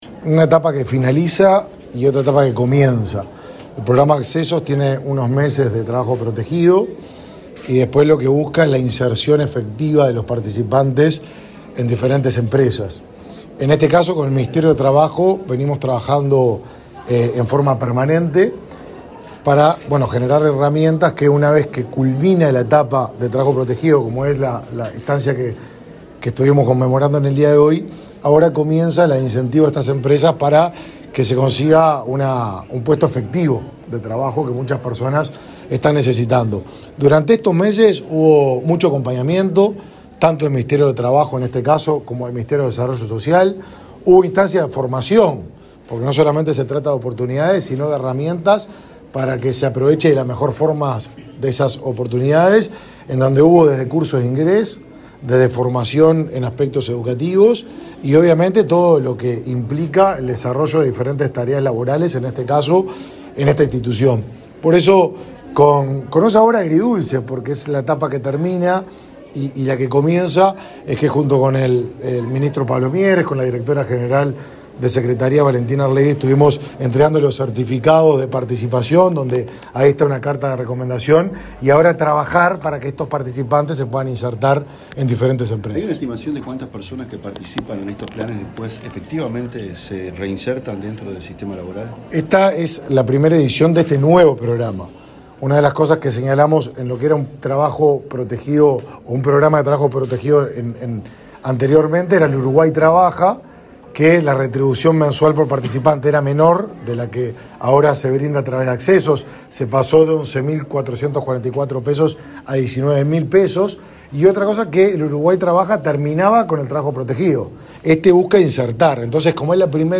Declaraciones a la prensa del ministro de Desarrollo Social, Martín Lema
Luego Lema dialogó con la prensa.